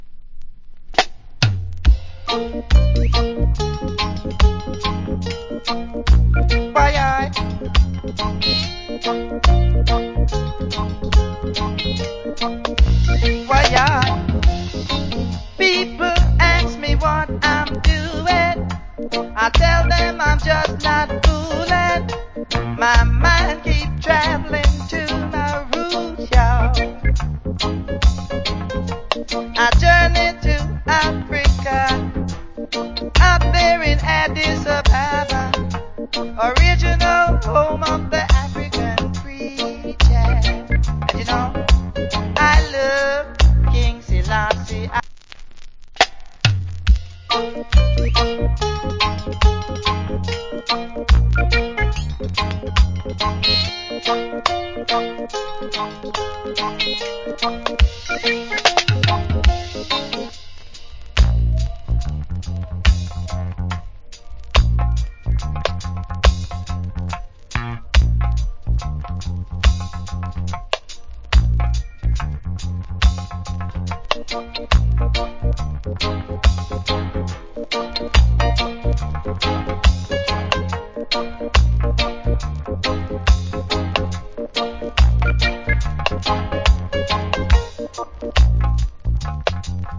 Nice Roots.